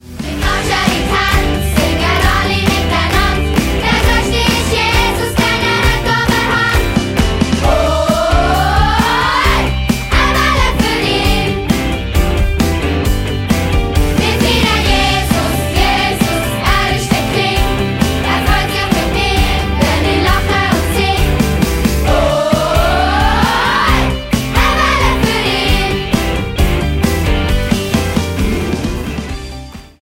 Mundartworship für Kids und Preetens